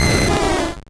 Cri de Draco dans Pokémon Rouge et Bleu.